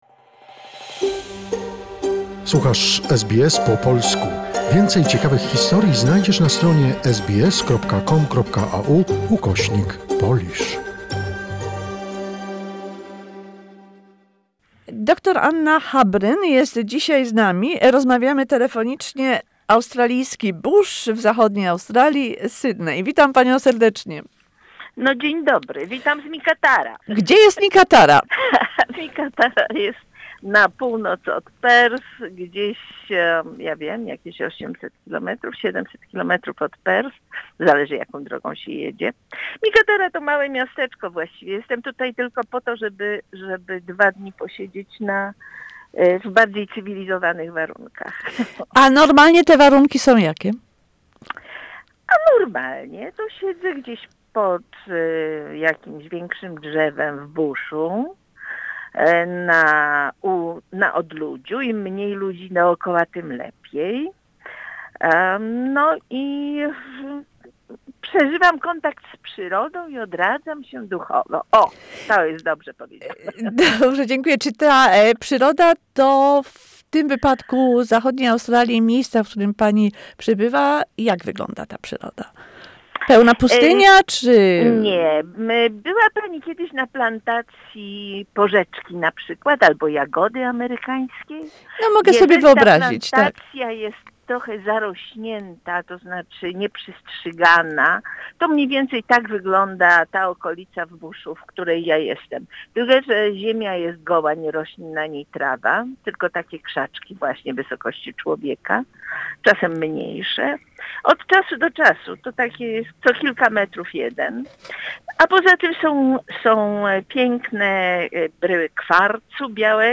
Edmund Pawel Strzelecki discovered gold in Australia but he kept the promise to be silent. An interview